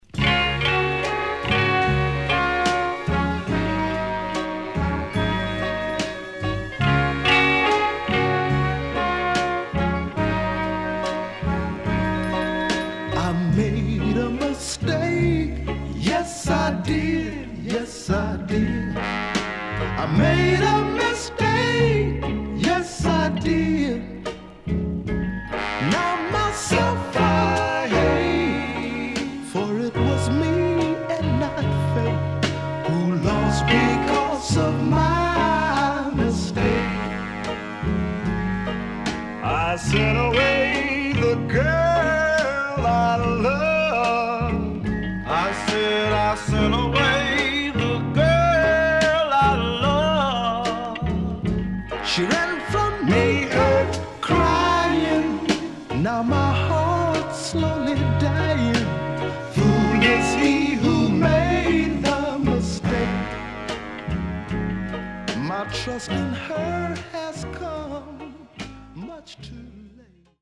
64年両面極上バラード。
SIDE A:少しチリノイズ入りますが良好です。